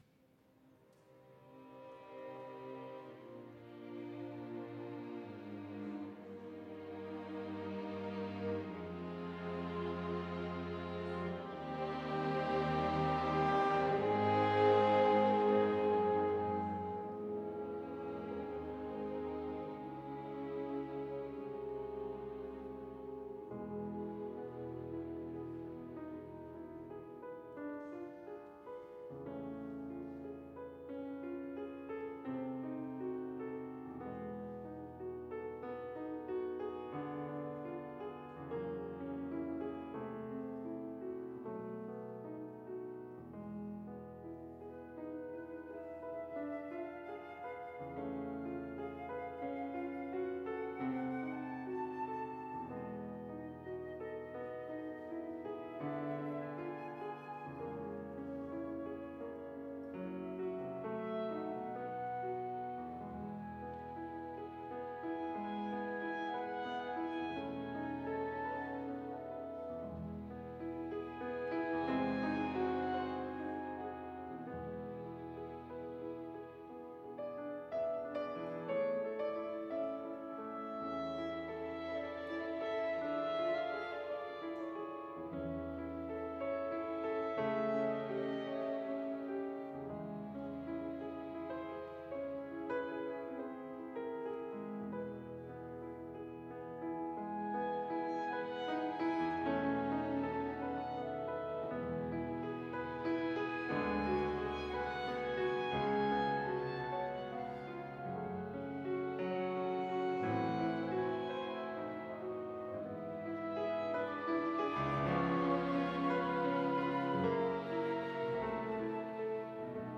Rachmaninoff Piano Concerto No.2 - II. Adagio sostenuto